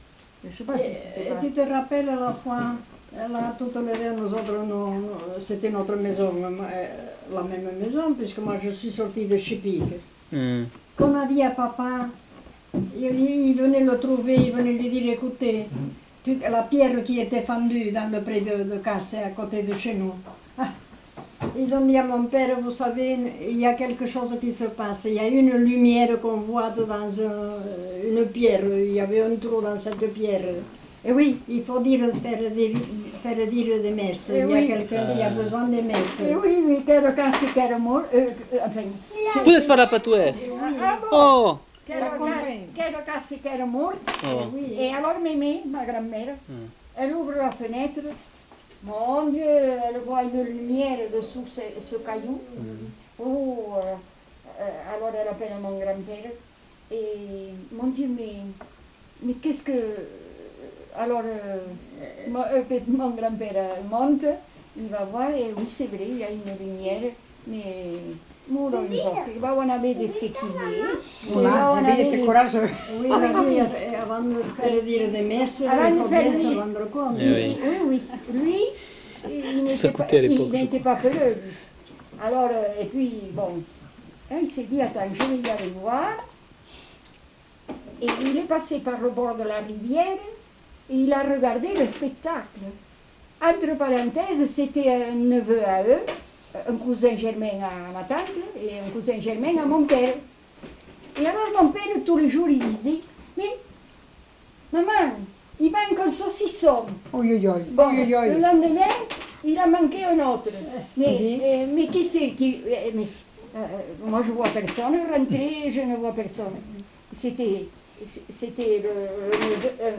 Lieu : Montjoie-en-Couserans
Genre : conte-légende-récit
Type de voix : voix de femme
Production du son : parlé
Classification : récit de peur